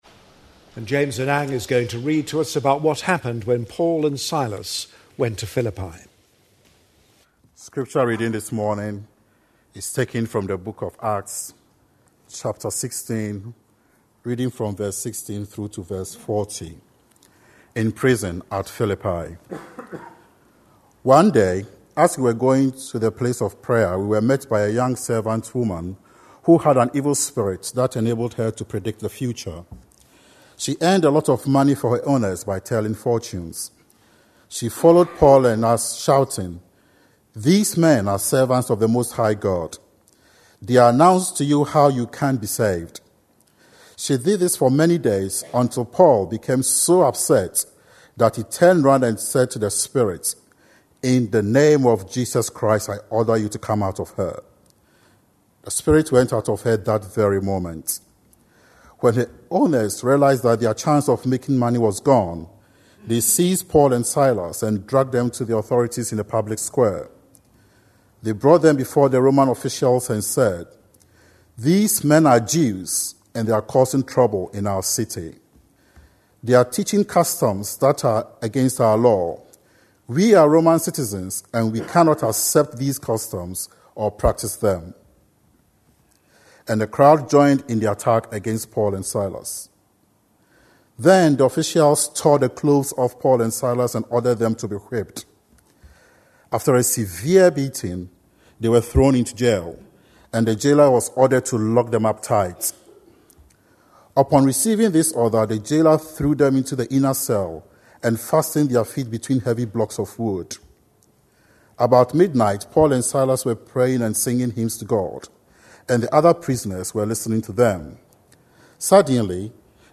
A sermon preached on 13th June, 2010, as part of our Acts series.